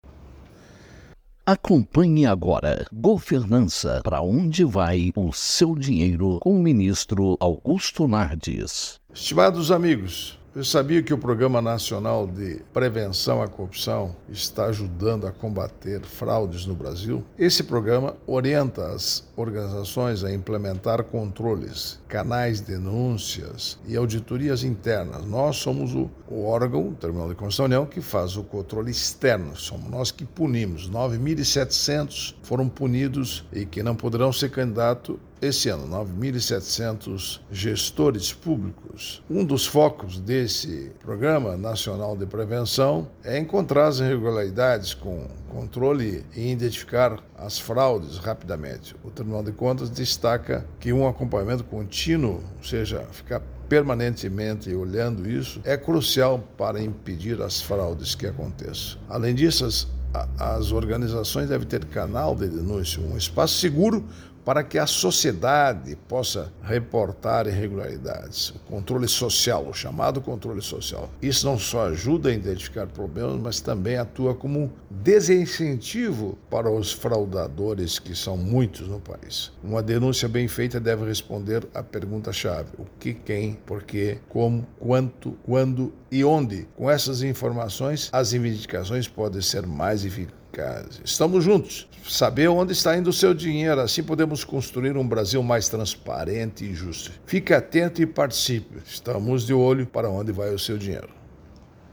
Comentário do ministro do TCU, Augusto Nardes, desta sexta-feira (04/10/24).